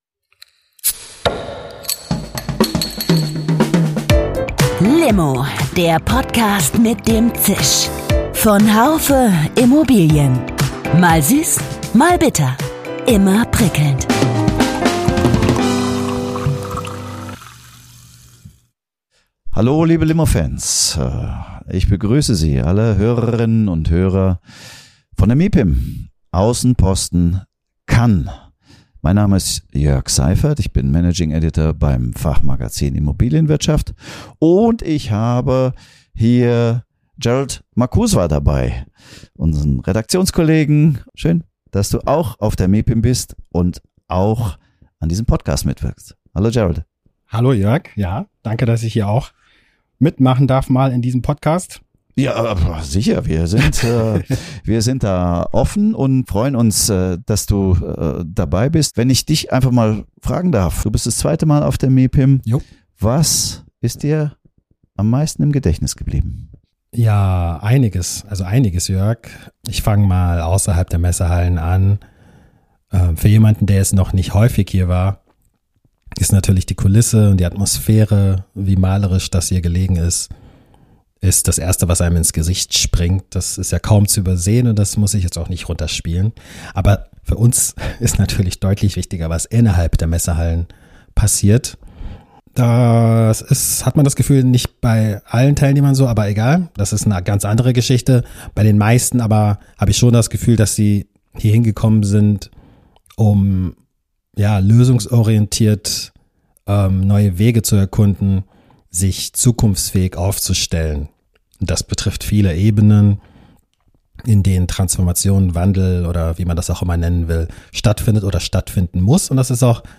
Perspektiven und Stimmen von der MIPIM in Cannes.
Eine Folge von L'Immo mit vielen Perspektiven auf den deutschen Markt – direkt von der MIPIM in Cannes.